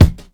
KICK_HOLY_SHIT.wav